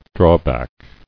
[draw·back]